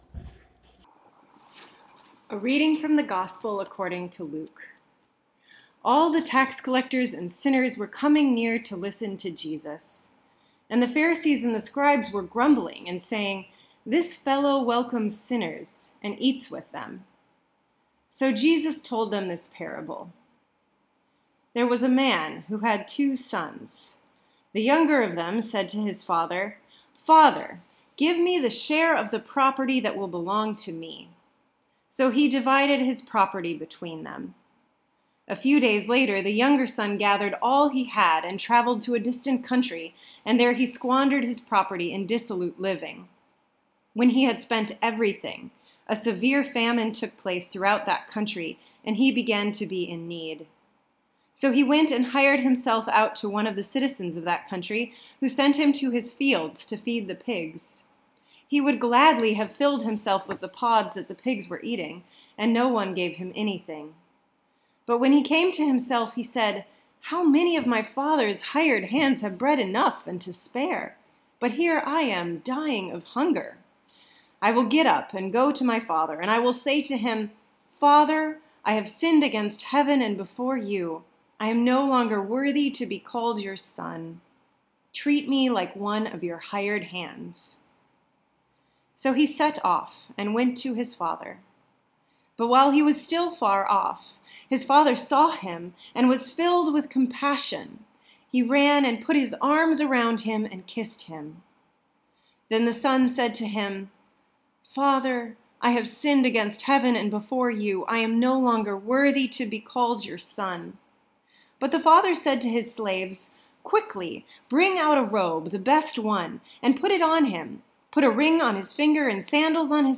April 1, 2019 Sermon